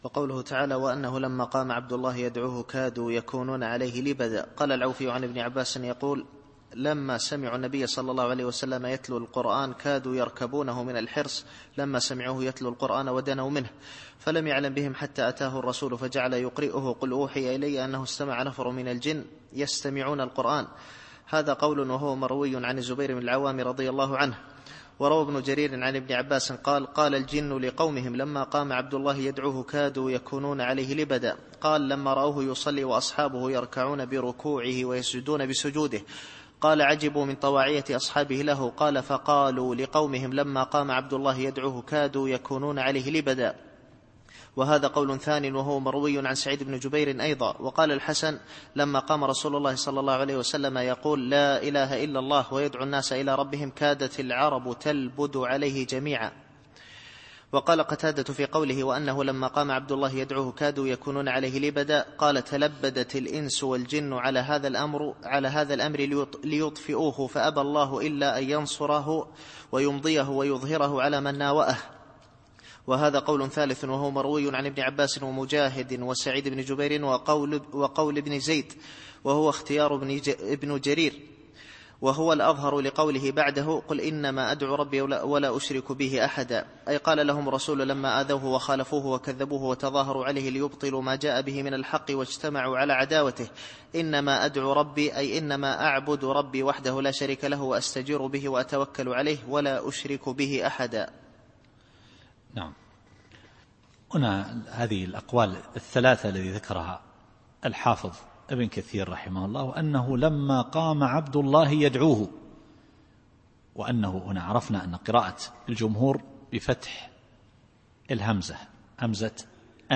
التفسير الصوتي [الجن / 19]